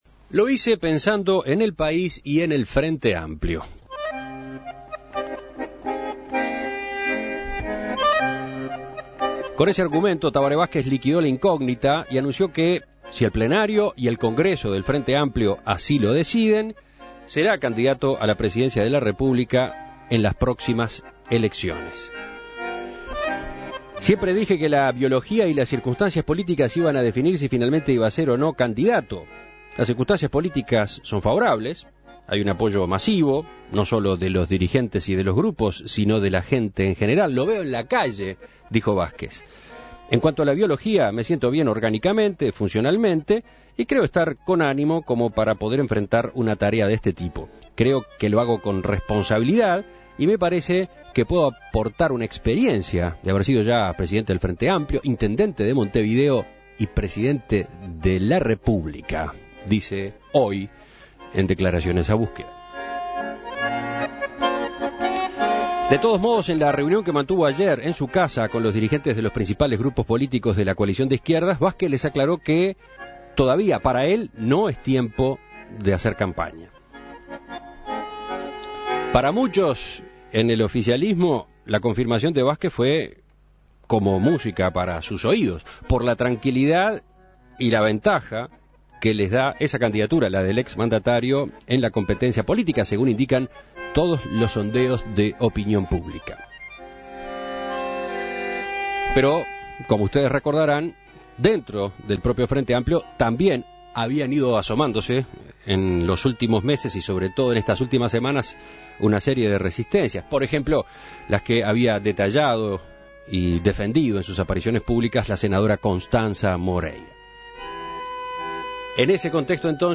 Escuche la entrevista a Julio Bango